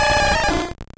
Cri de Carapuce dans Pokémon Rouge et Bleu.